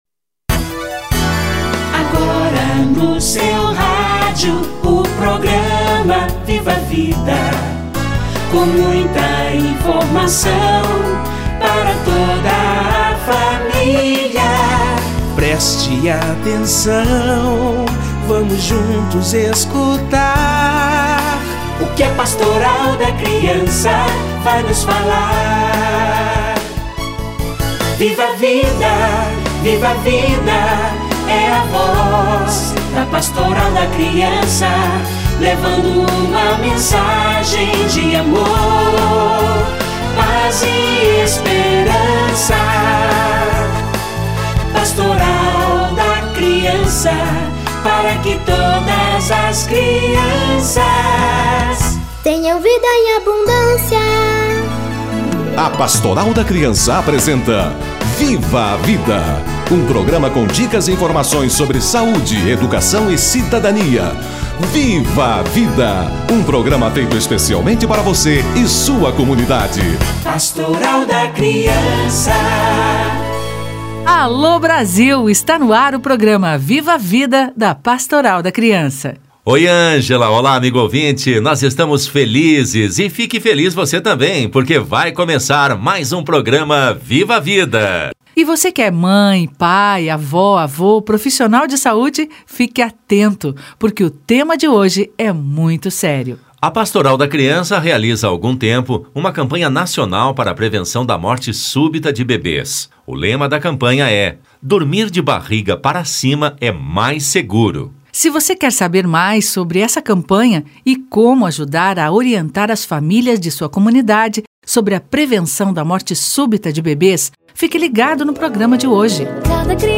Dormir de barriga para cima é mais seguro - Entrevista